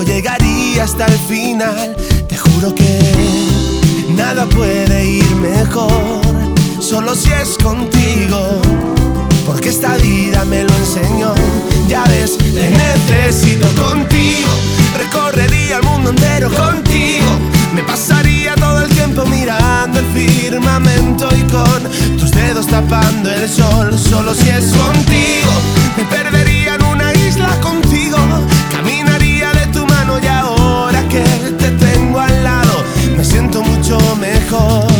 Pop
Жанр: Поп музыка